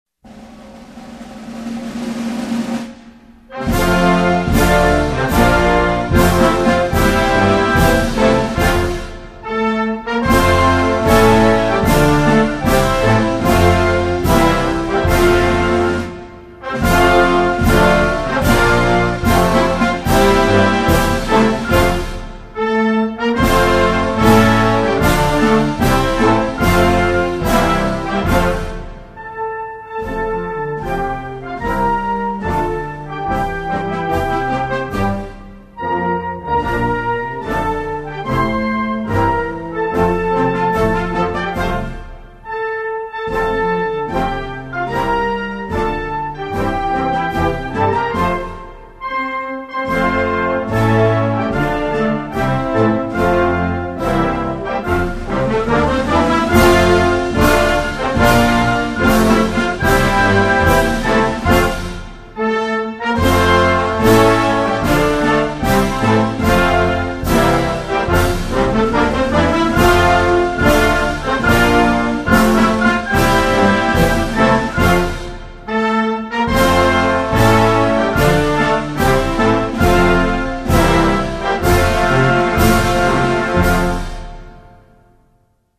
Egypt-Anthem.mp3